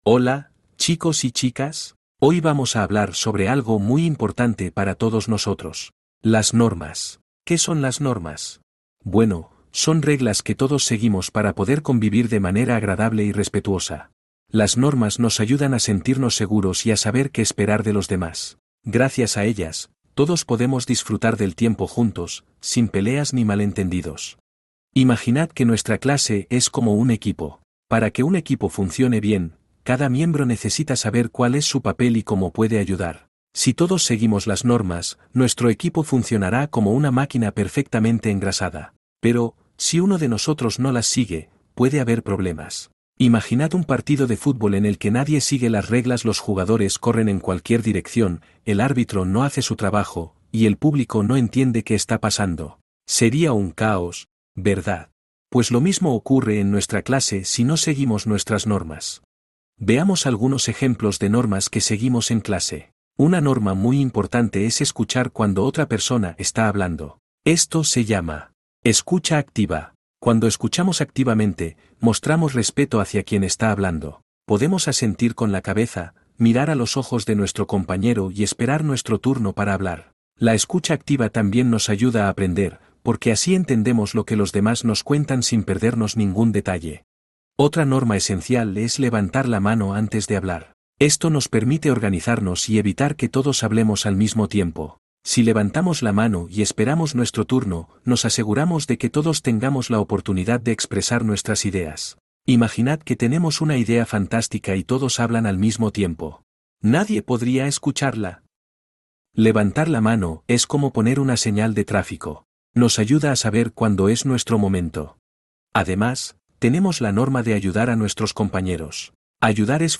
Normas básicas - audición
Guion pregrabado para la actividad de listening.